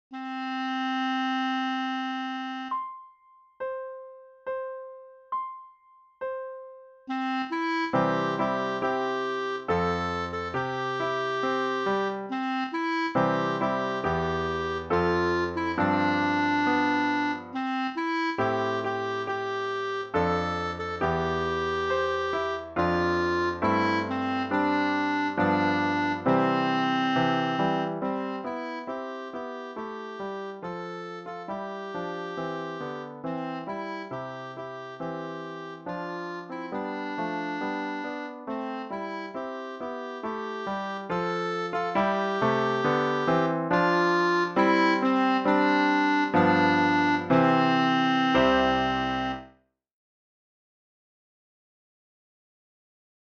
Ici, on pourra acceder à une version accompagnée des mélodies et chansons apprises lors de nos cours.
Une belle chanson “spiritual” qui nous aide à aprende la formule rythmique “Timri” 🙂